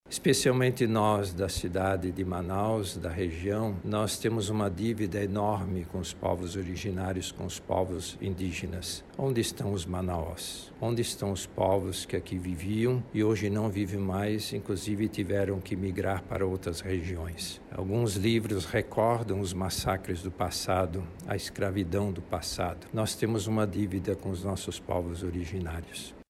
A declaração foi feita durante um pronunciamento em que o religioso falou sobre o papel dos povos originários na formação do Brasil.